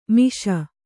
♪ miṣa